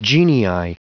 Prononciation du mot genii en anglais (fichier audio)
Prononciation du mot : genii